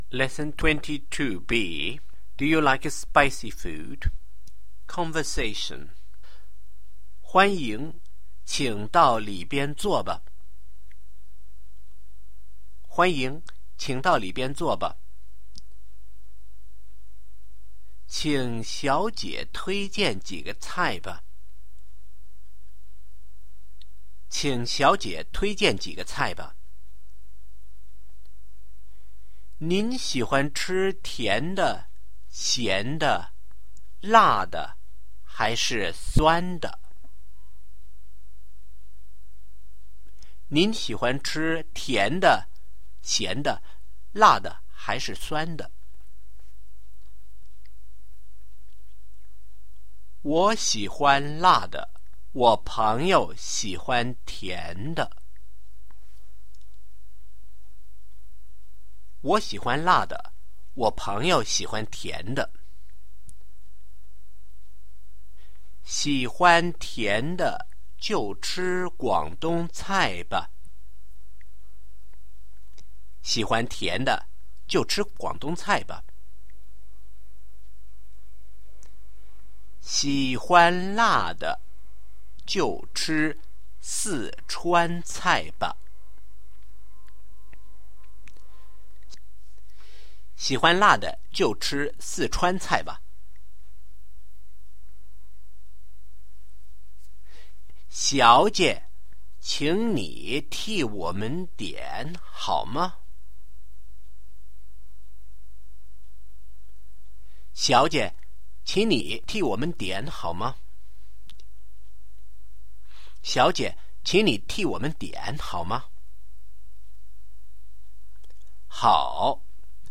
Conversation
Tape version